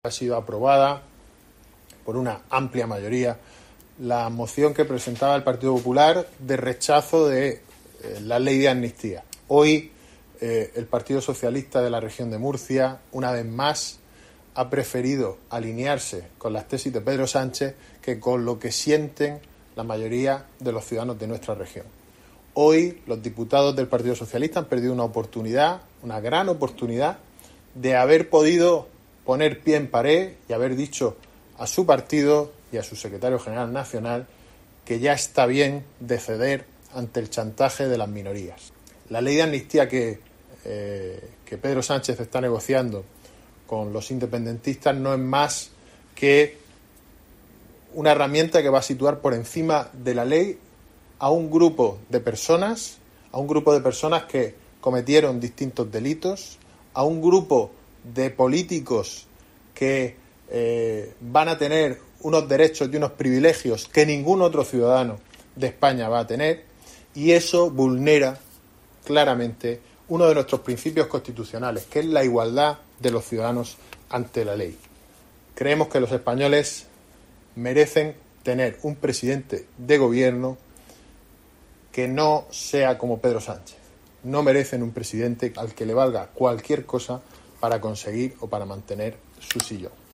Joaquín Segado, portavoz del GPP